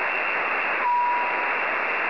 При проведении MS связи на частоте 144.370 Мгц, прозвучал странный секундный бип.